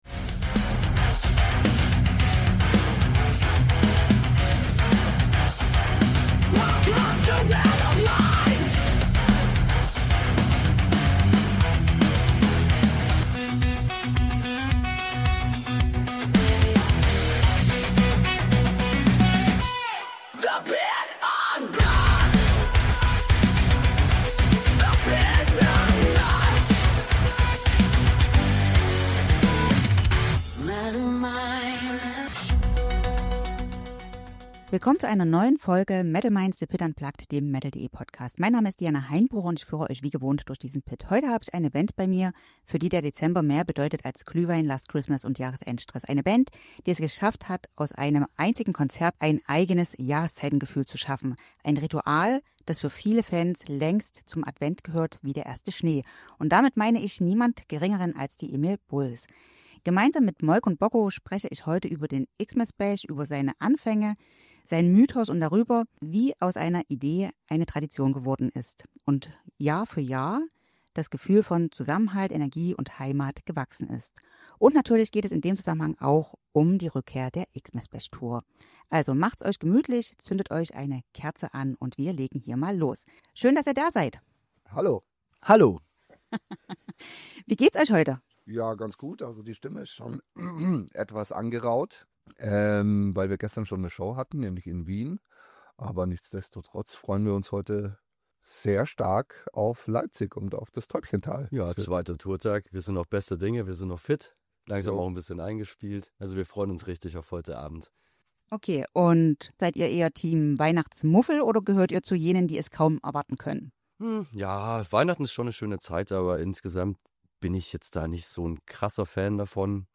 Ein Gespräch über Traditionen, Verbundenheit, Liebe zur Musik und die Freude, jedes Jahr wieder mit Fans ein musikalisches Jahresendritual zu feiern.